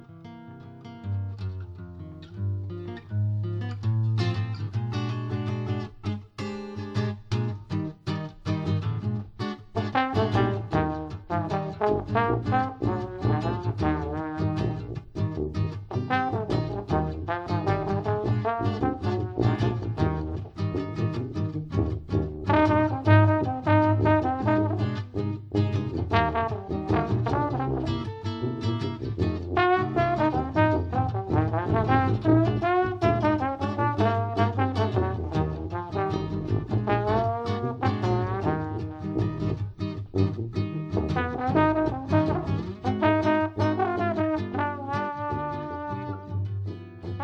• Versatile, genre-hopping jazz ensemble
• Traditional jazz but with a modern twist
• Inspired by '20s/'30s New Orleans music